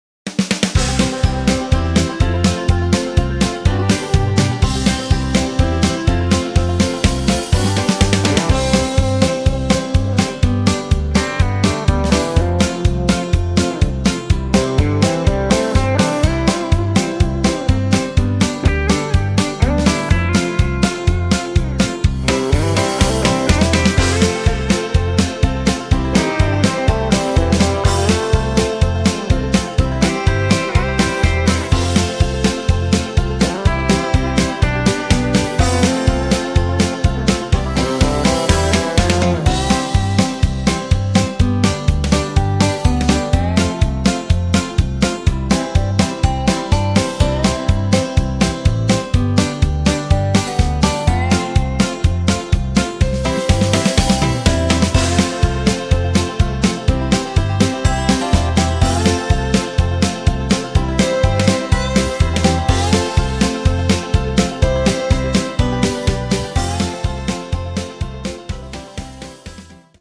Square Dance Music
(Patter)
Music sample